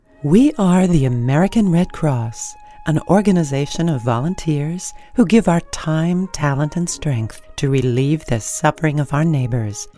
Female
Radio Commercials
Red Cross Ad:Mature, Comfortin
All our voice actors have professional broadcast quality recording studios.